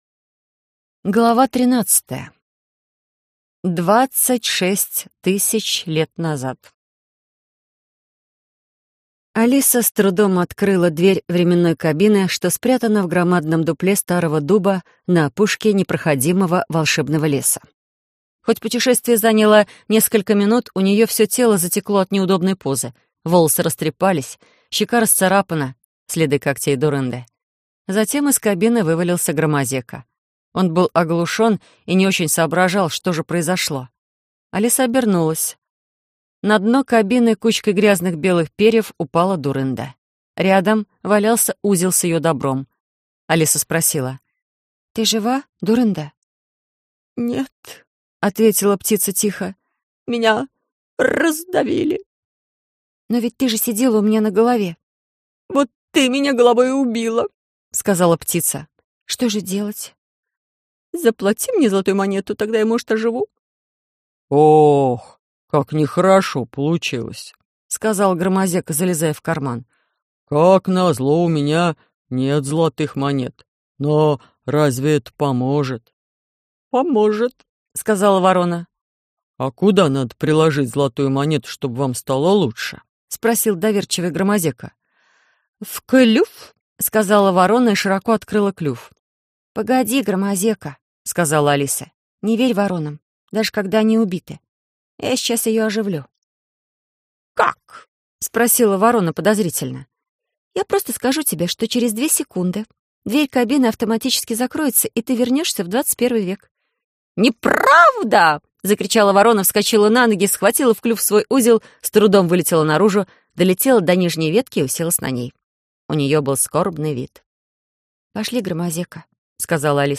Аудиокнига Лиловый шар | Библиотека аудиокниг